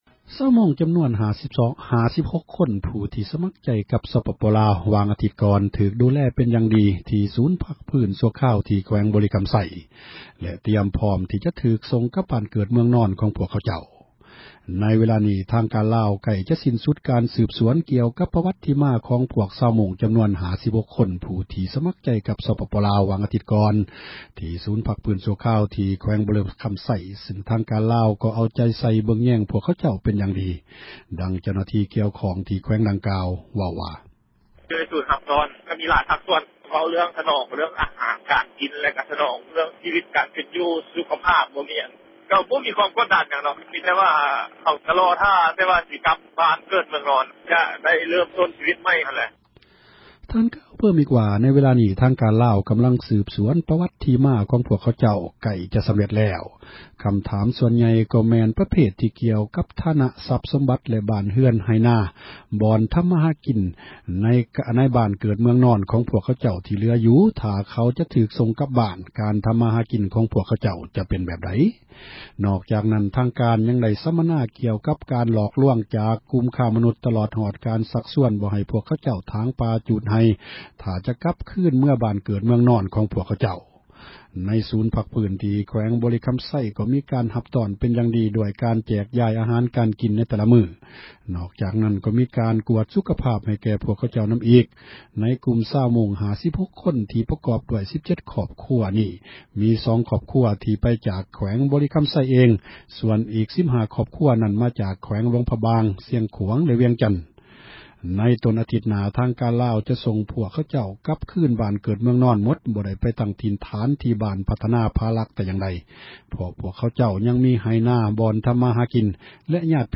ສະພາບ ປະຈຸບັນ ຂອງ ຊາວມົ້ງ 56 ຄົນ ໃນ ສປປລາວ — ຂ່າວລາວ ວິທຍຸເອເຊັຽເສຣີ ພາສາລາວ